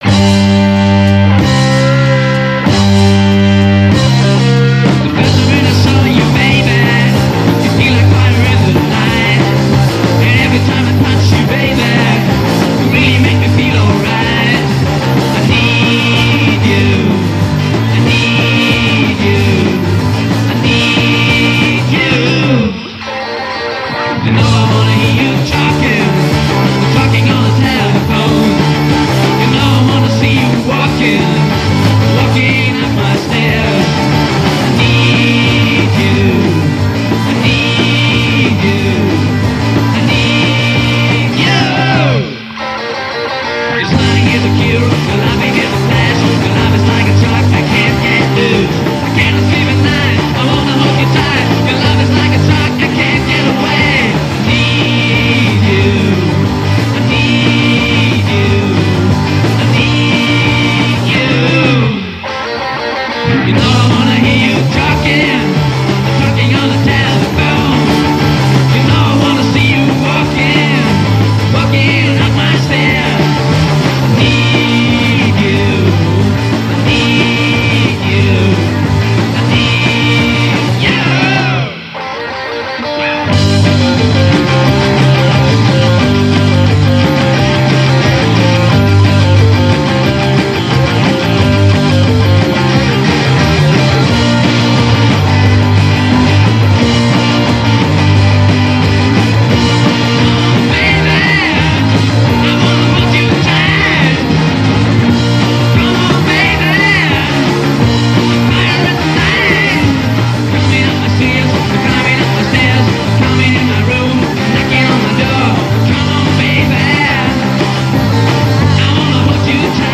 recorded on a 4-track-recorder @ Kiefernstr
drums